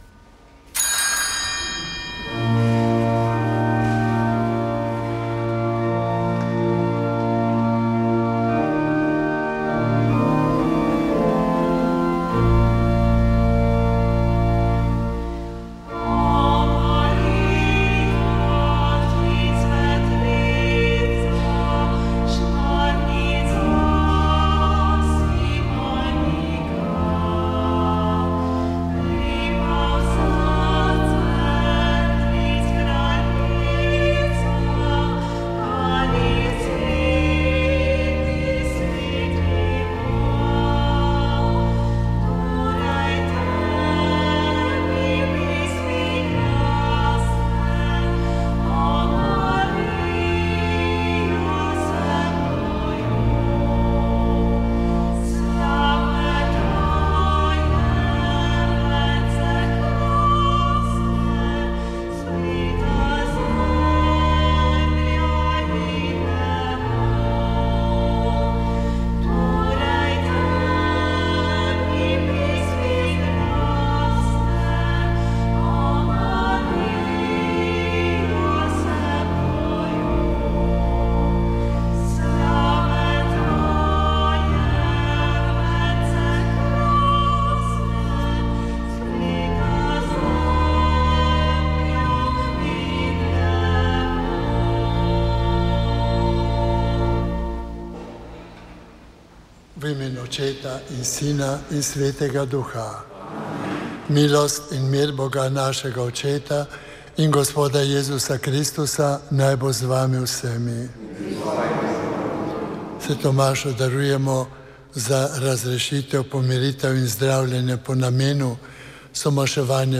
Sv. maša iz bazilike Marije Pomagaj na Brezjah 6. 5.